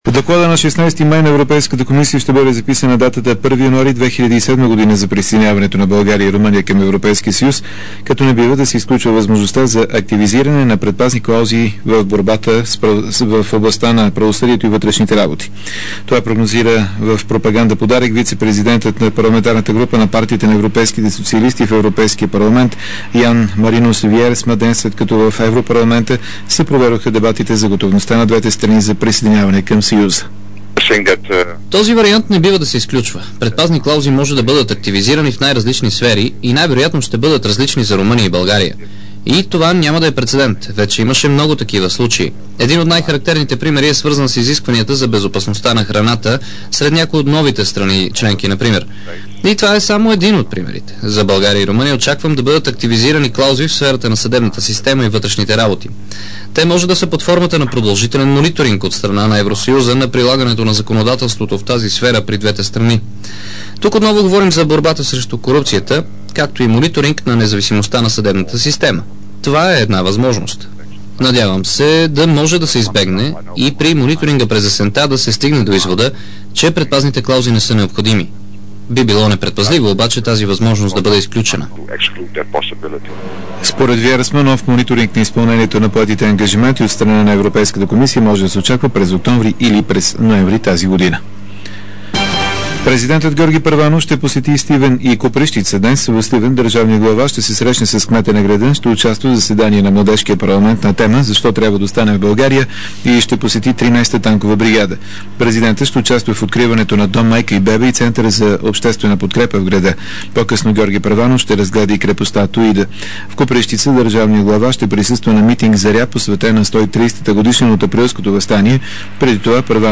DarikNews audio: Обедна информационна емисия 30.04.2006